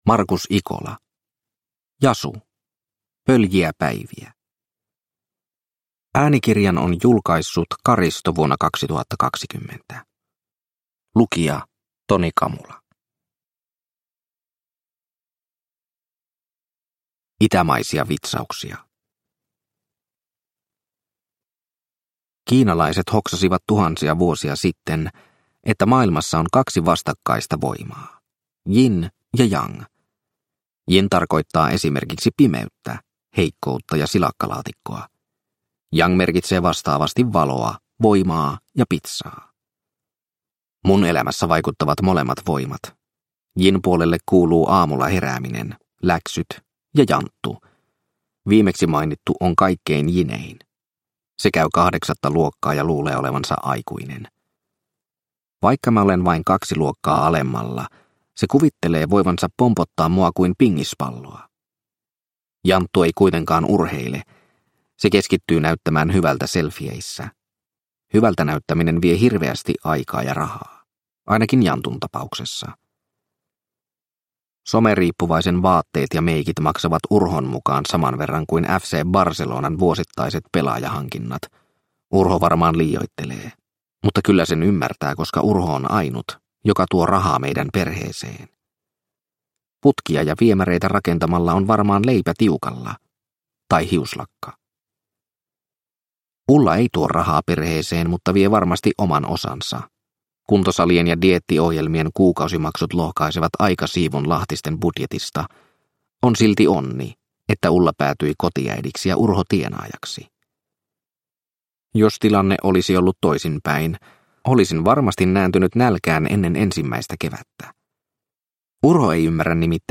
Jasu - Pöljiä päiviä – Ljudbok – Laddas ner